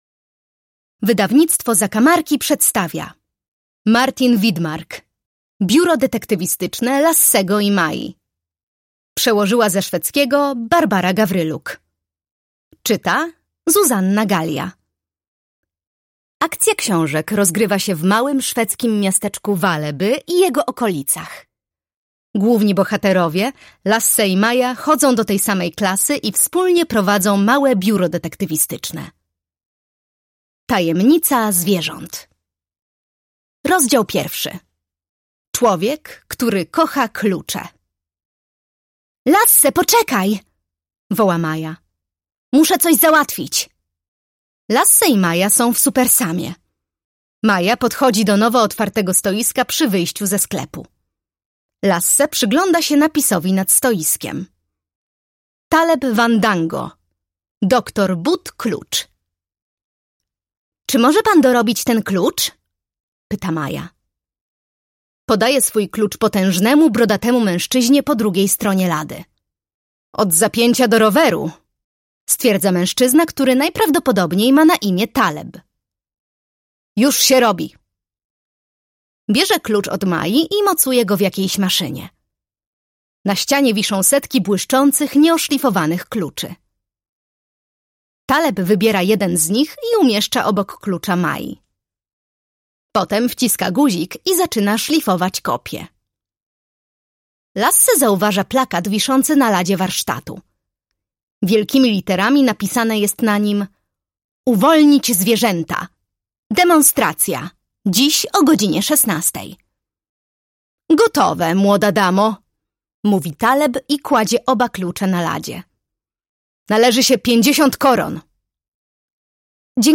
Biuro Detektywistyczne Lassego i Mai. Tajemnica zwierząt - Martin Widmark - audiobook